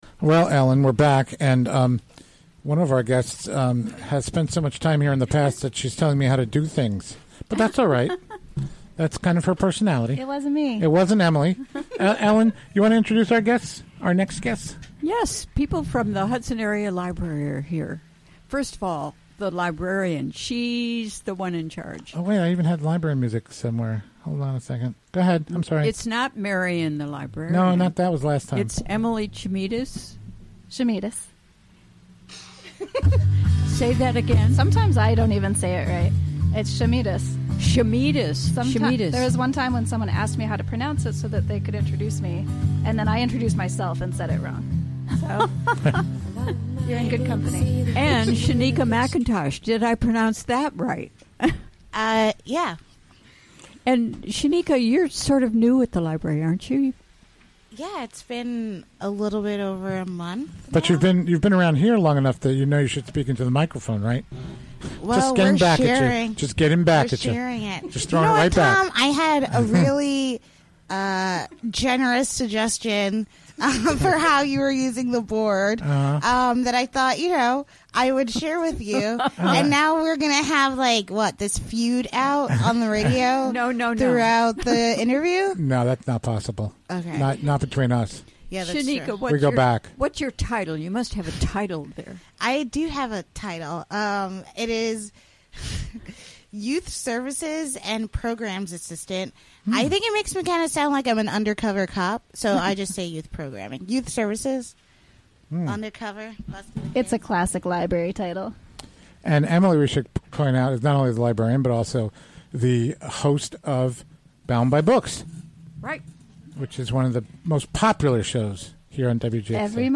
In this broadcast, an in-studio conversation with...
Recorded live during the WGXC Afternoon Show Thu., Apr. 6, 2017.